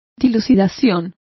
Complete with pronunciation of the translation of explanation.